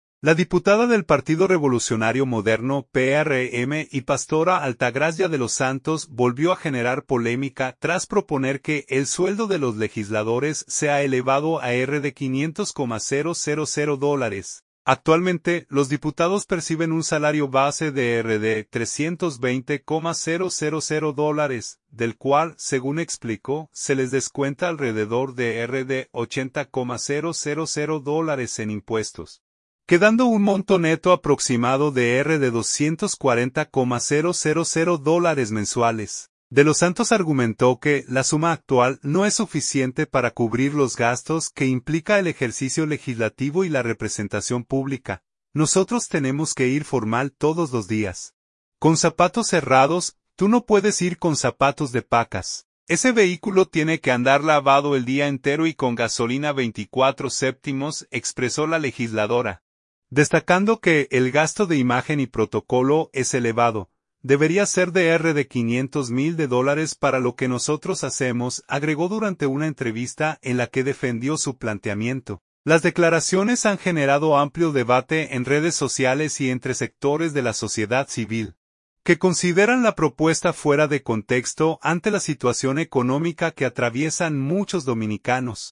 “Debería ser de RD$500 mil para lo que nosotros hacemos”, agregó durante una entrevista en la que defendió su planteamiento.